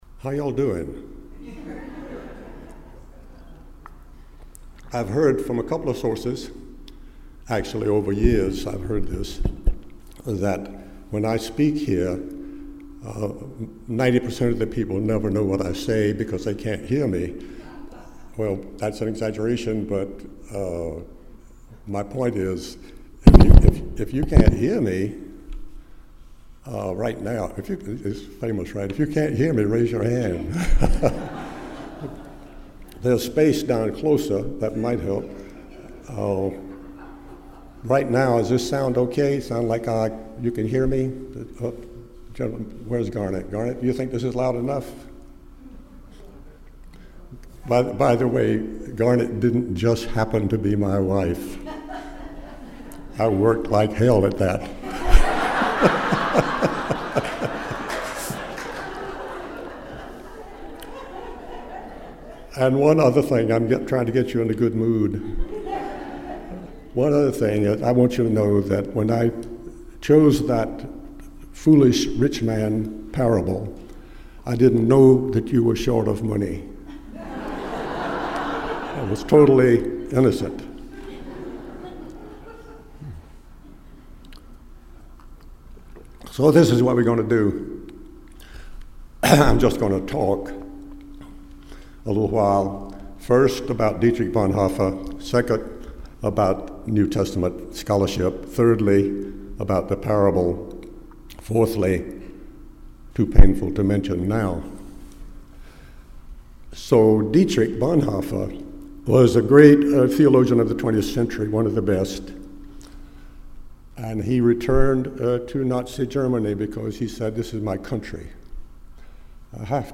The sermon raises the question originally asked by Dietrich Bonhoeffer from prison in 1945: Is it possible for Christianity to be relevant in the modern world when the supernaturalism of the story is called into question by modernity itself. There are clues as to what Jesus was about that we will examine….apart from supernaturalism.